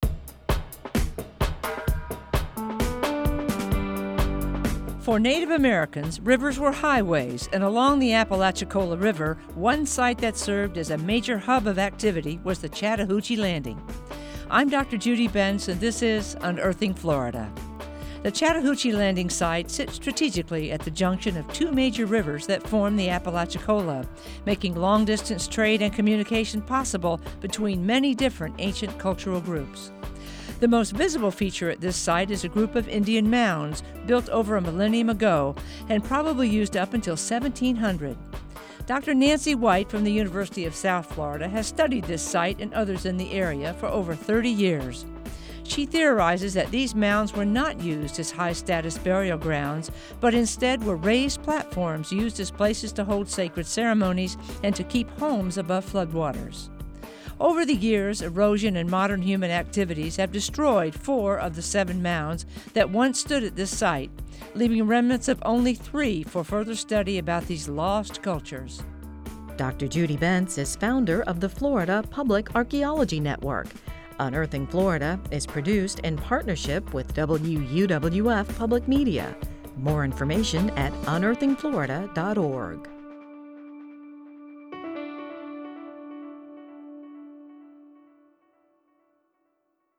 Written, narrated, and produced by the University of West Florida, the Florida Public Archaeology Network, and WUWF Public Media.